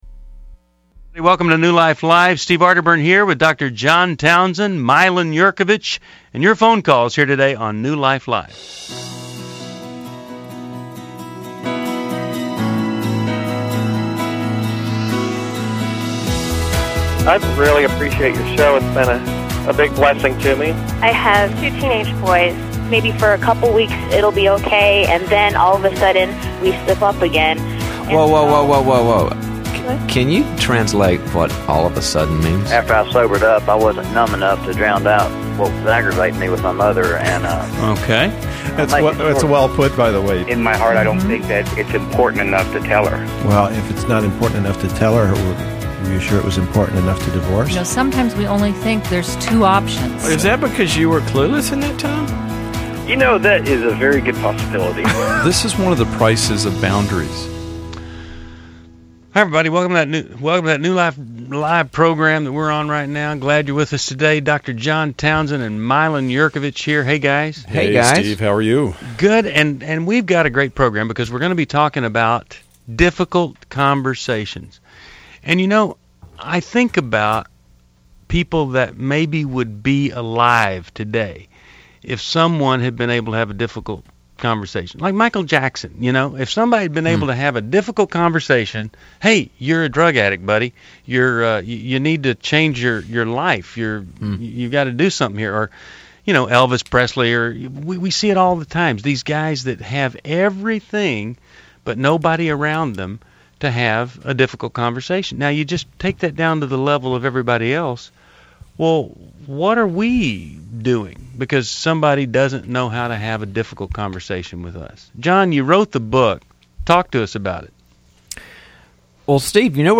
Explore relationship dilemmas on New Life Live: November 4, 2011, as callers tackle affairs, marriage struggles, and difficult conversations.